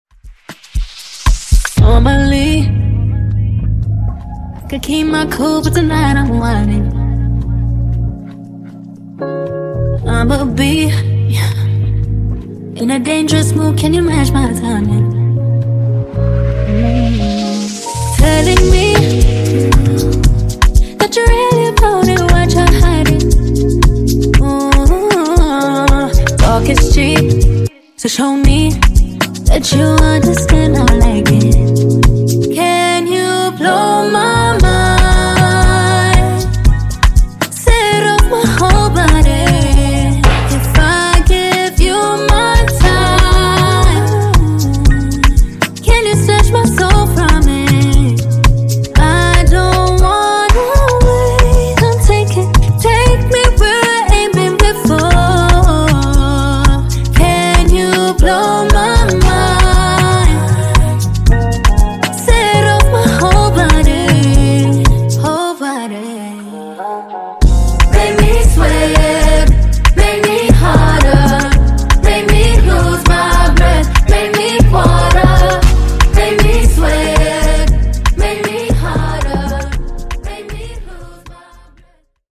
BPM: 117 Time